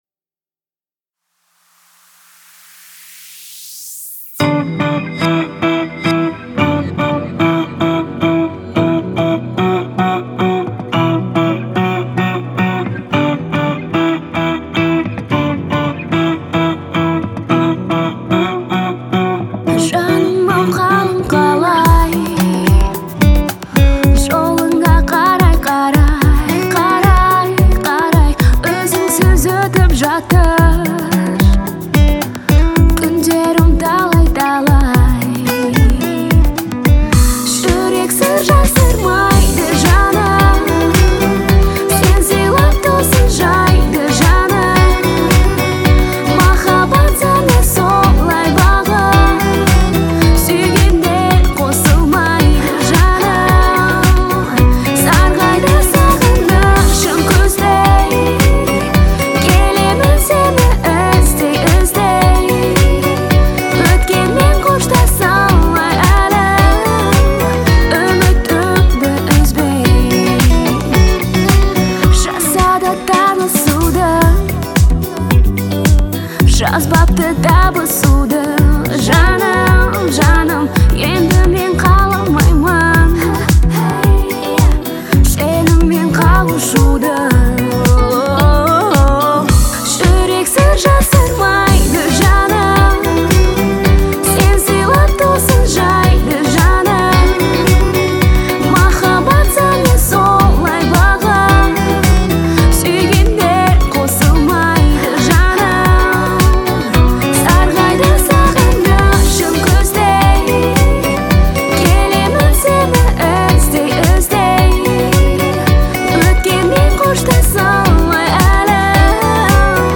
казахстанской певицы